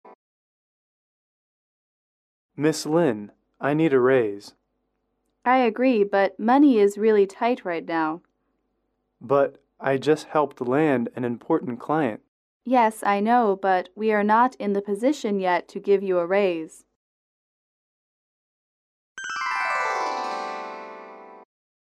英语口语情景短对话11-2：要求加薪(MP3)